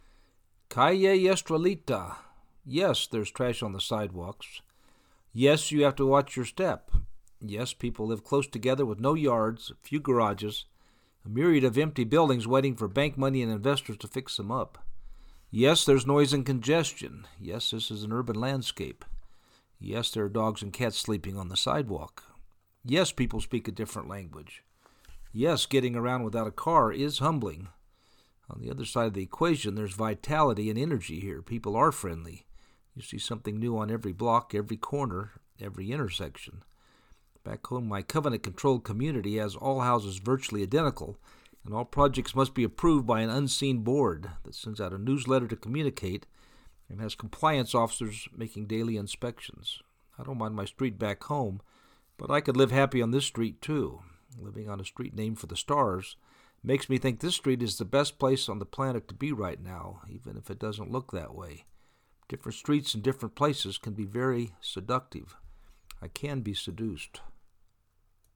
Calle Estrellita In the neighborhood
Yes, there is noise and congestion. Yes, this is an urban landscape.